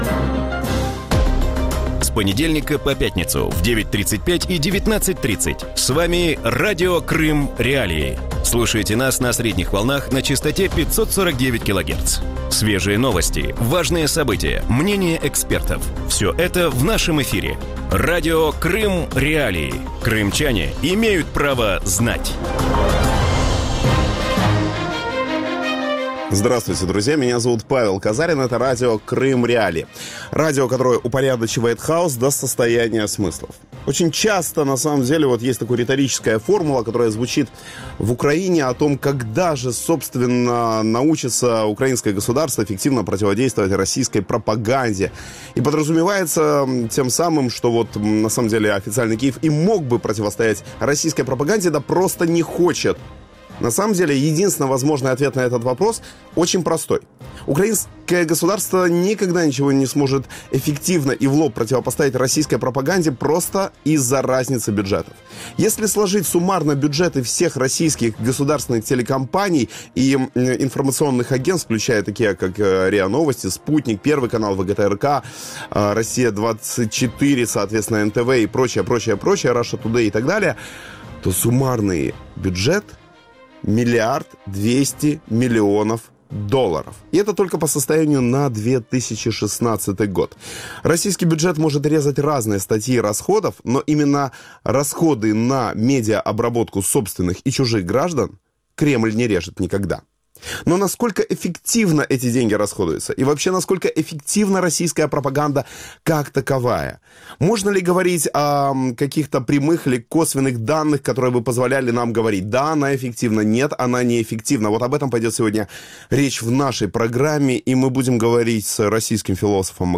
У вечірньому ефірі Радіо Крим.Реалії говорять про те, як працює російська пропаганда і коли вщухне її ефект. За якими ознаками оцінюється ефективність інформаційного впливу і як кримчанам від нього захиститися?